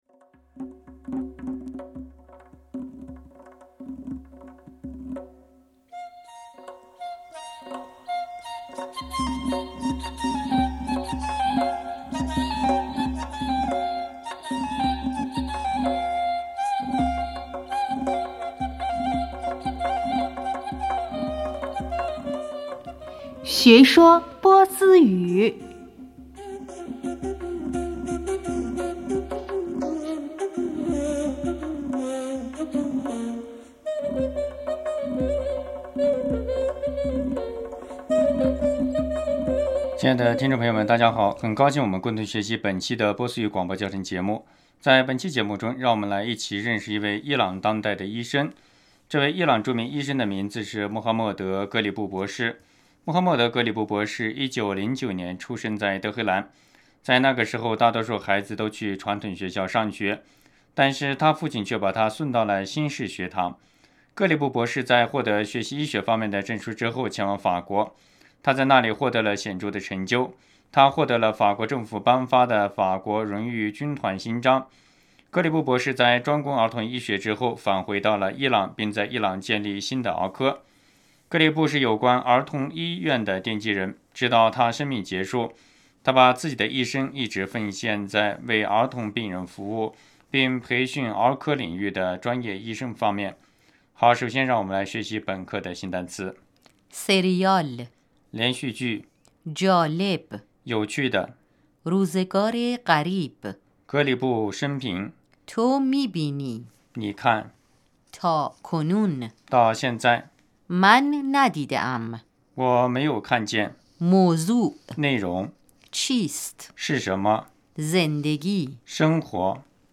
亲爱的听众朋友们，大家好！很高兴我们共同学习本期的波斯语广播教学节目。在本期节目中让我们一起来认识一位伊朗当代的医生。